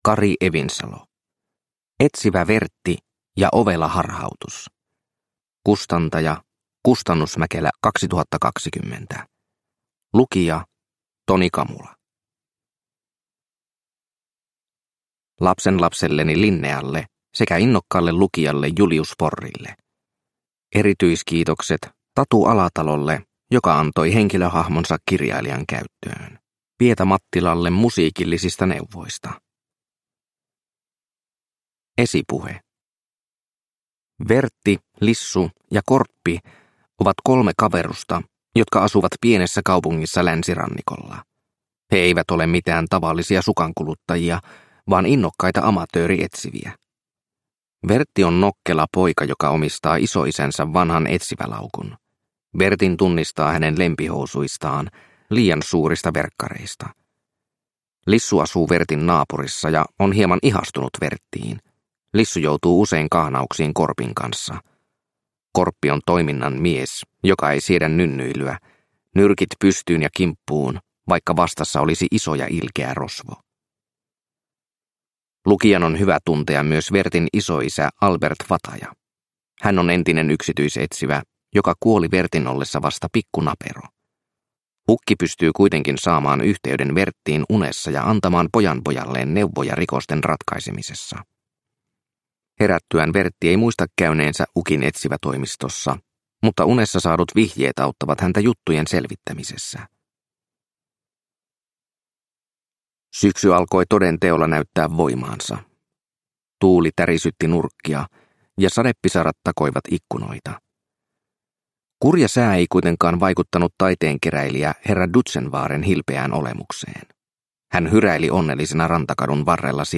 Etsivä Vertti ja ovela harhautus – Ljudbok – Laddas ner